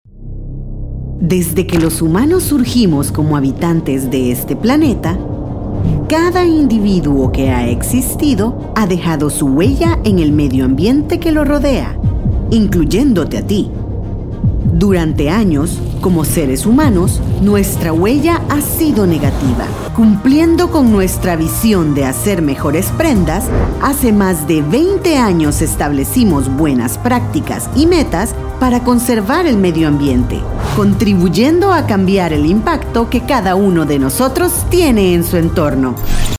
Native Spanish speaker, english voiceover, cheerful, bright, serious, convincing, conversational, corporate, educational, commercial spots.
Sprechprobe: Industrie (Muttersprache):